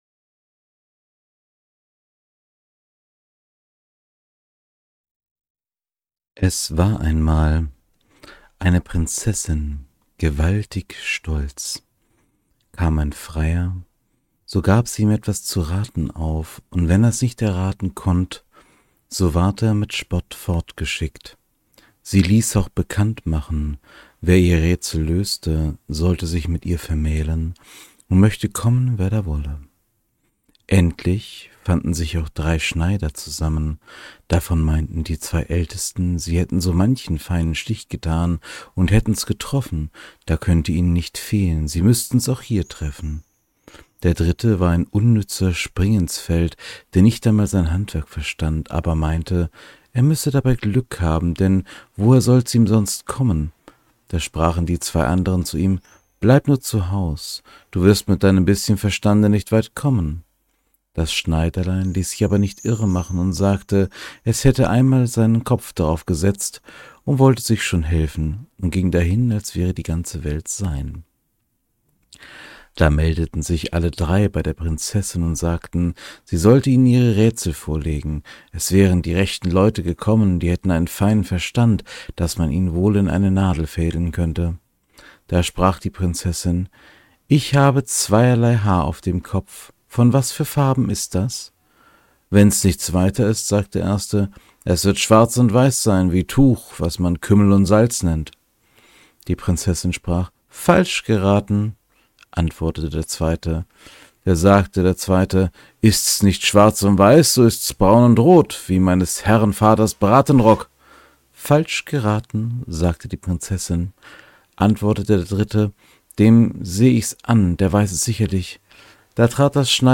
In diesem kleinen Podcast Projekt lese ich Märchen vor.